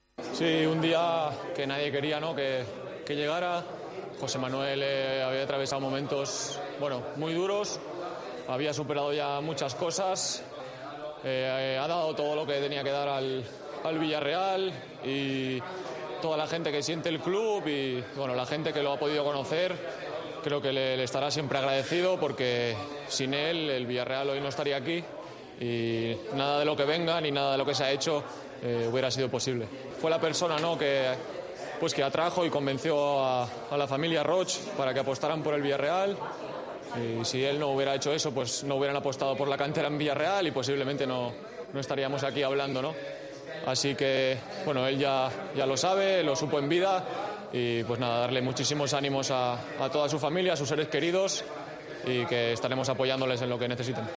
AUDIO: El canterano del Villarreal habló muy emocionado en Movistar del fallecimiento de José Manuel Llaneza, vicepresidente del equipo.